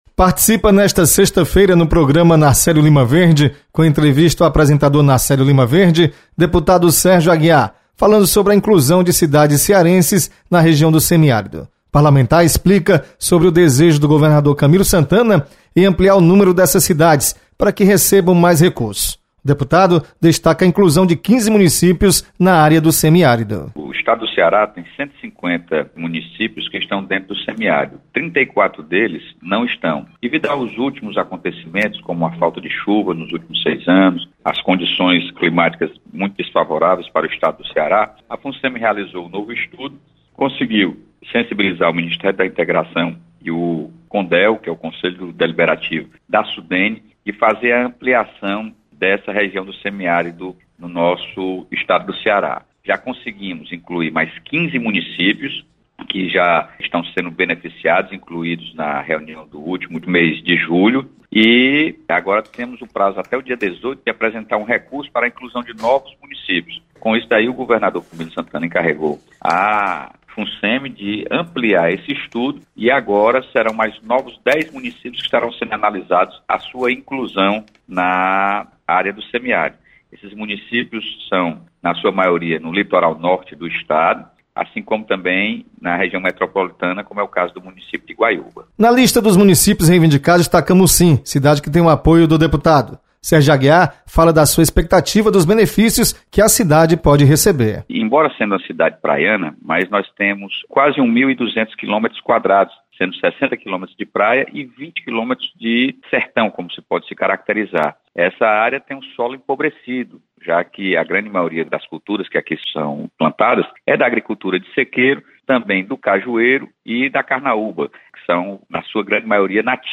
Deputado Sérgio Aguiar defende mais recursos para cidades do semiárido cearense. Repórter